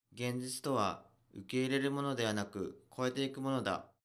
ボイス
男性
dansei_genzitutohaukeirerumonodehanakukoeteikumonoda.mp3